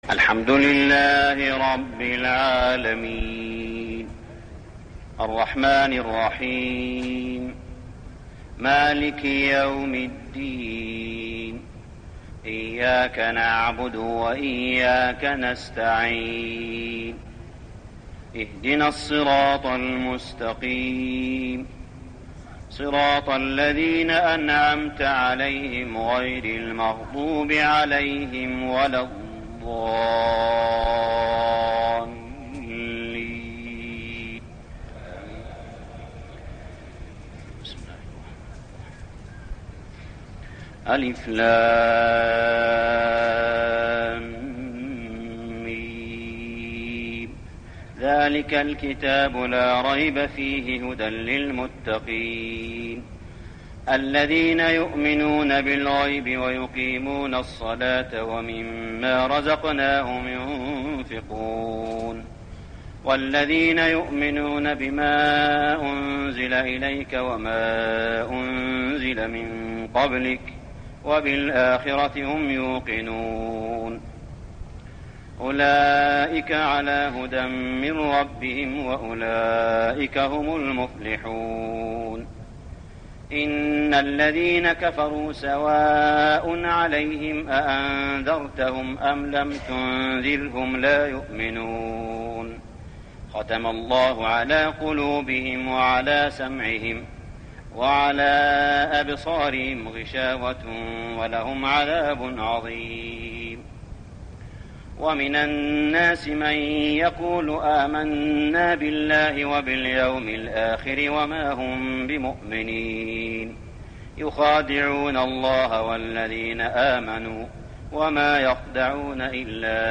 صلاة التراويح ليلة 2-9-1410هـ سورة البقرة 1-77 | Tarawih Prayer Surah Al-Baqarah > تراويح الحرم المكي عام 1410 🕋 > التراويح - تلاوات الحرمين